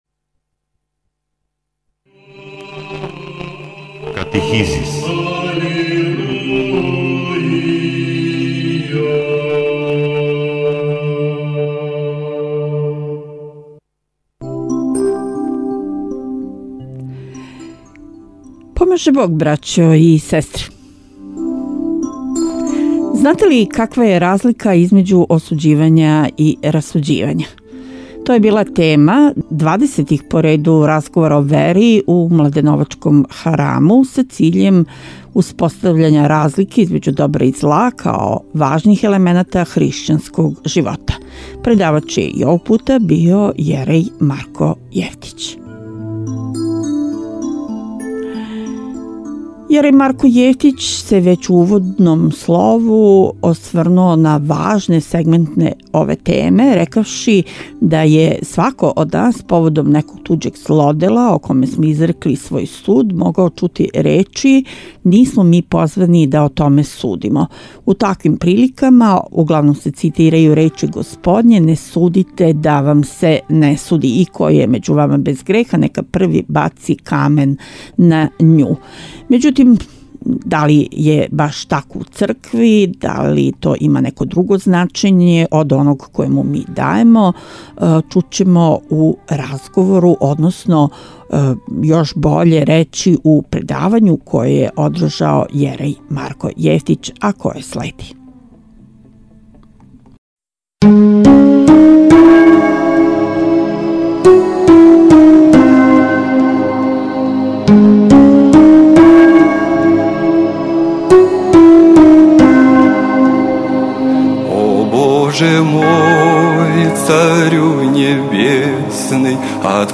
Осуђивање и расуђивање, тј. разликовање добра и зла, као важних елемената хришћанског живота била је тема нових Разговора о вери у младеновачком храму Успења Пресвете Богордице.